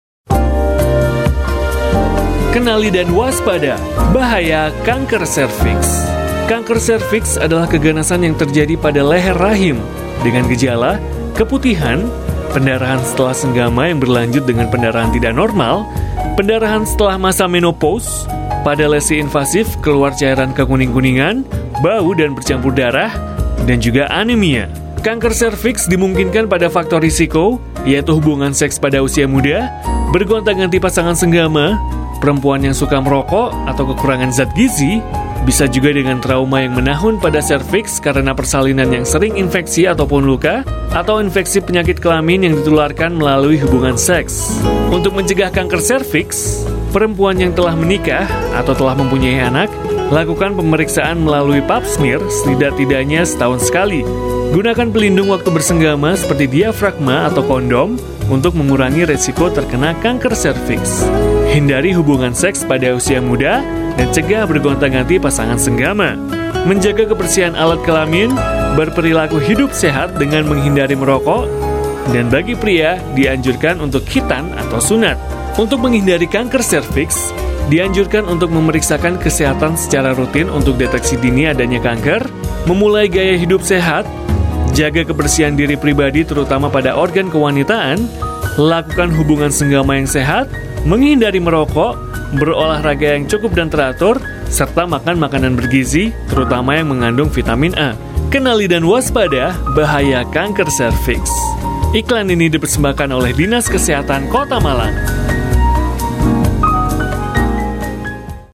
Indonesian Voice Over Talent
Sprechprobe: Industrie (Muttersprache):